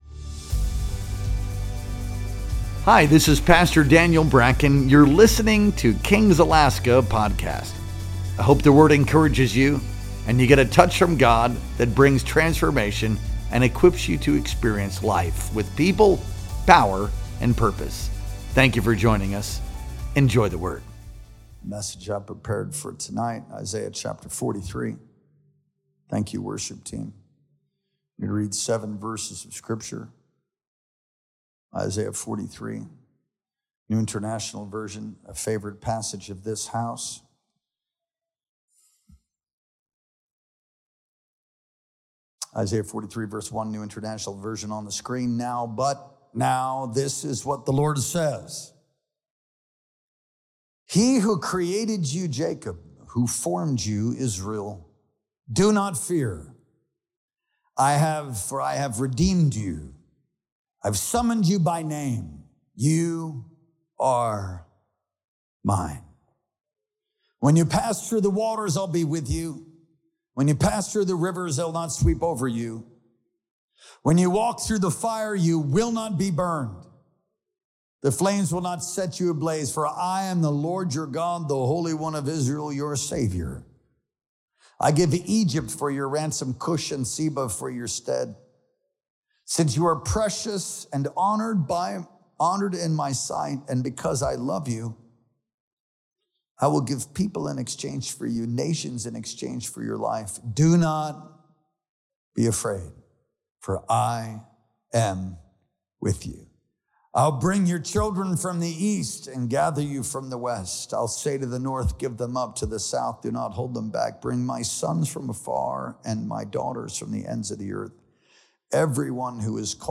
Our Sunday Night Worship Experience streamed live on August 3rd, 2025.